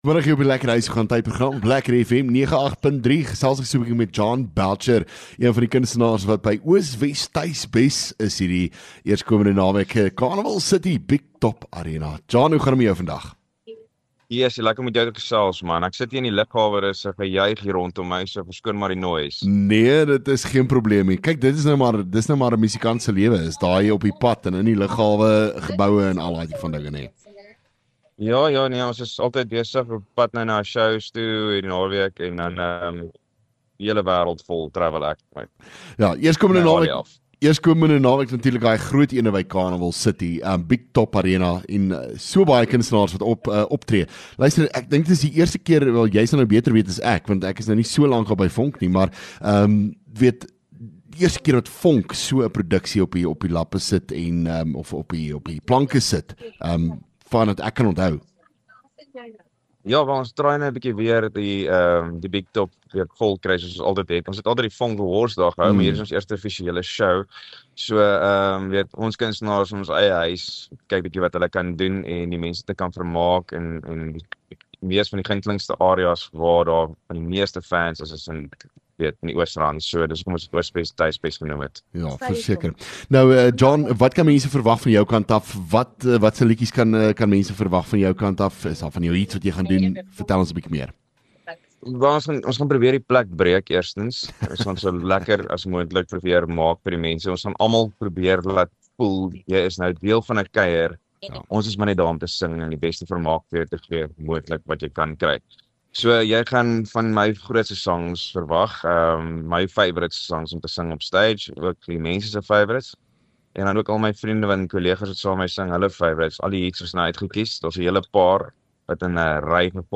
LEKKER FM | Onderhoude 31 Mar Oos Wes Tuis Bes